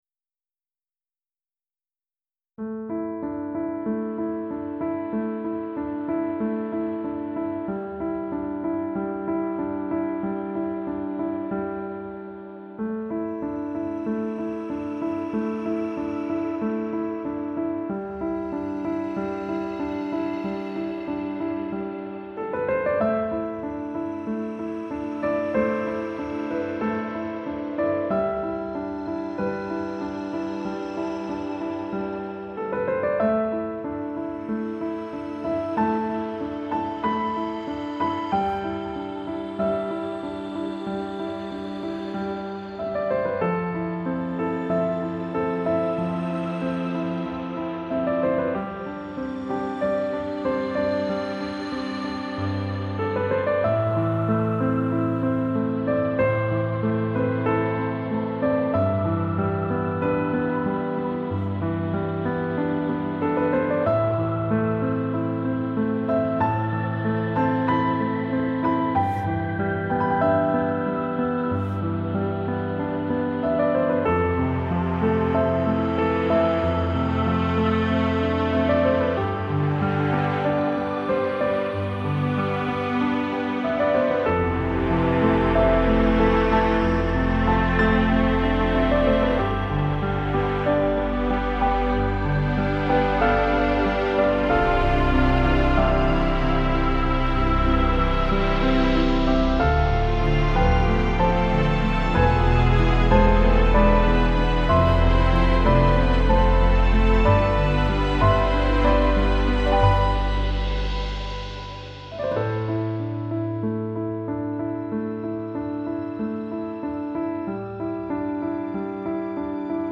موسیقی بی کلام پیانو عصر جدید عمیق و تامل برانگیز
موسیقی بی کلام نیو ایج